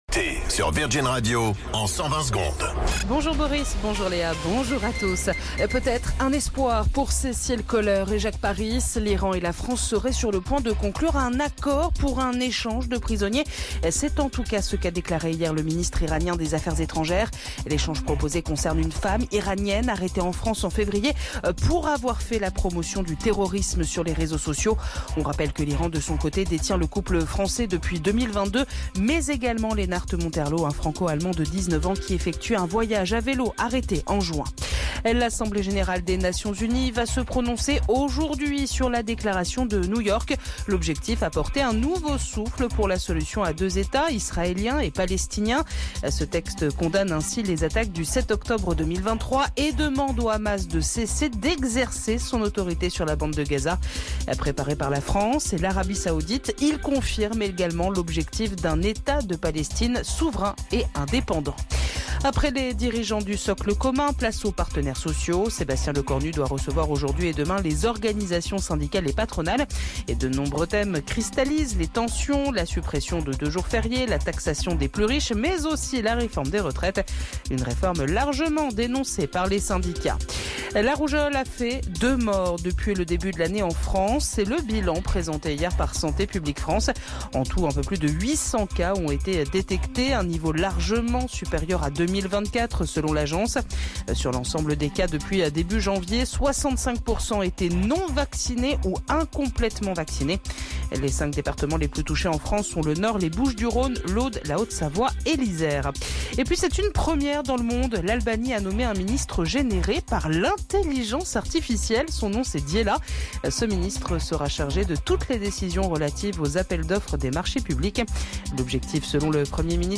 Flash Info National 12 Septembre 2025 Du 12/09/2025 à 07h10 .